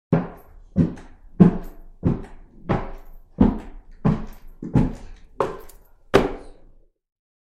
Звуки топота ног
Шум шагов по деревянному полу